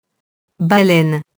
baleine [balɛn]